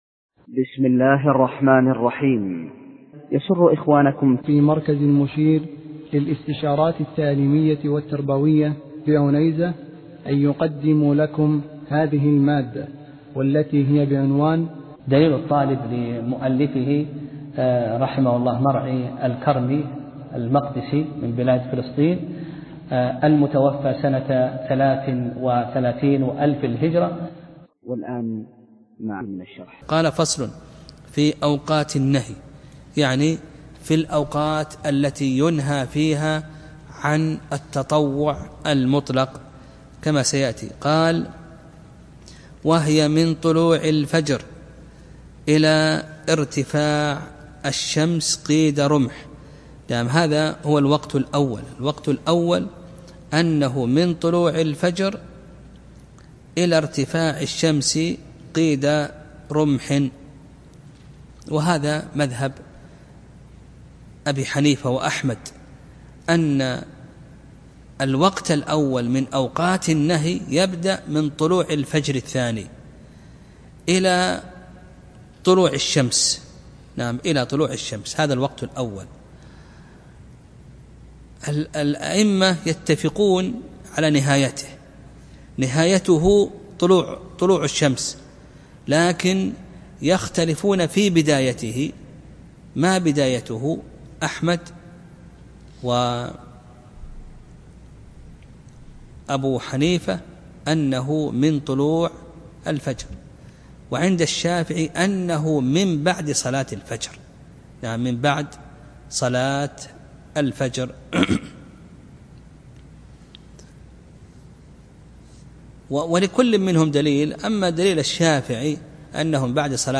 درس (7) : فصل في أوقات النهي